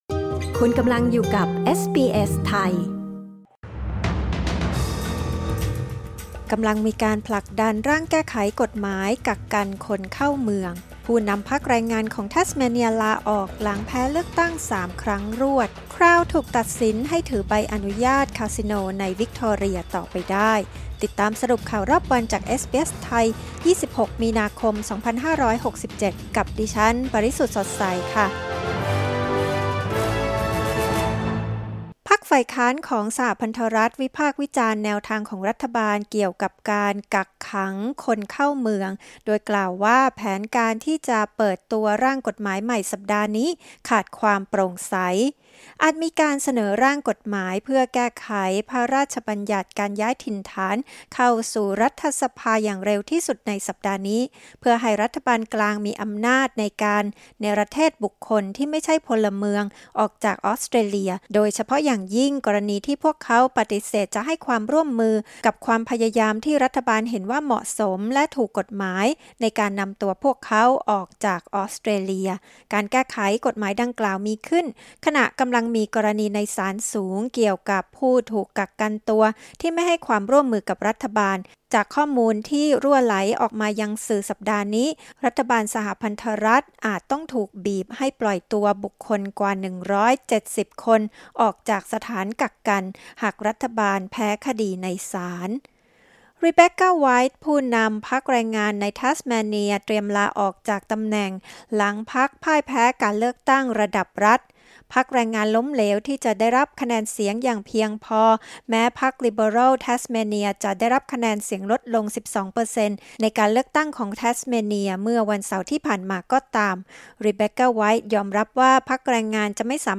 สรุปข่าวรอบวัน 26 มีนาคม 2567